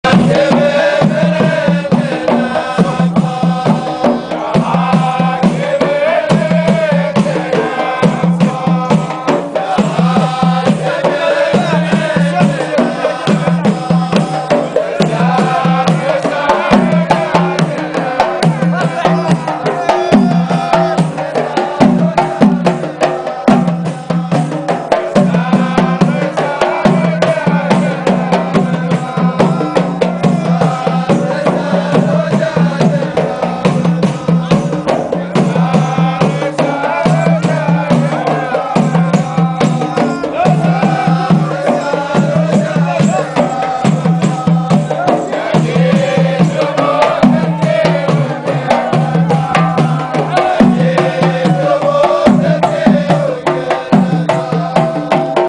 Carpeta: musica arabe mp3